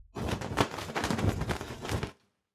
Cloth Shake Sound
household
Cloth Shake